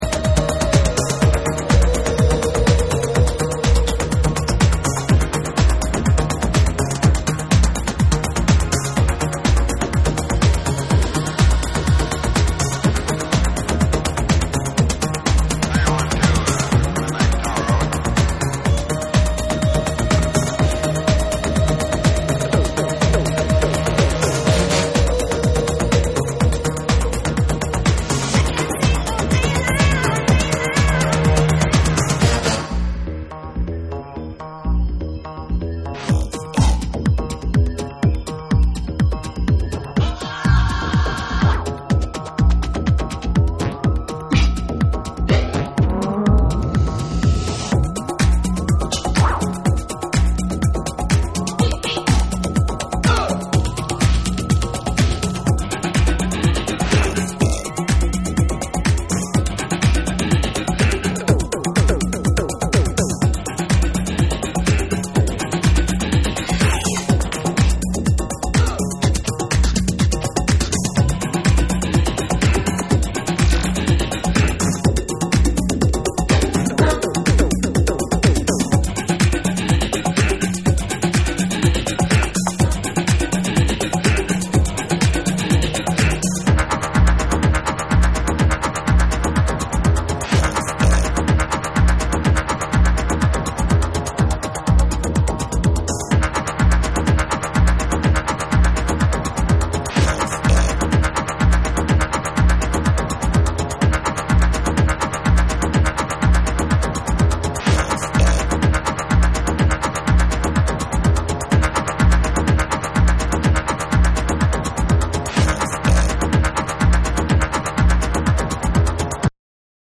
パーカッションも混ざるアシッド感満載な2など、重圧で強烈なエレクトリック・ダンスチューンを4ヴァージョン収録。